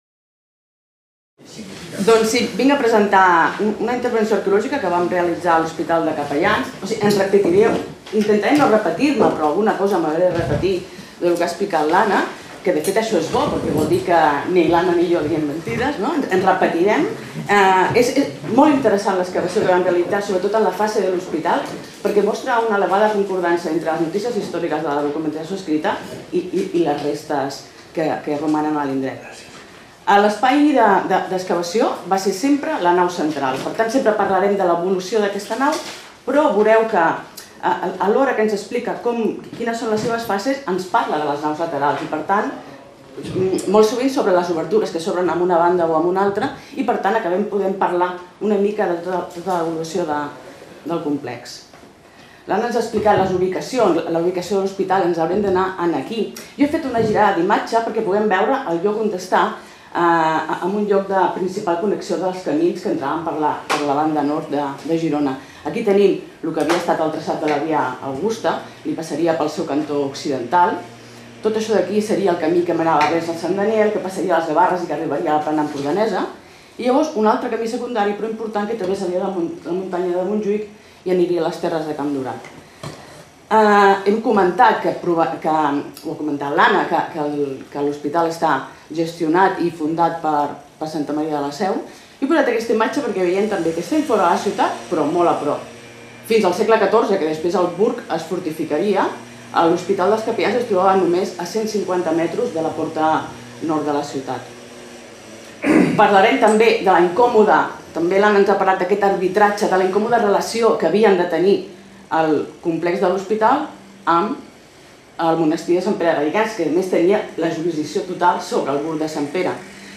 Comunicació: [Hospital dels Capellans (Burg de Sant Pere, Girona)]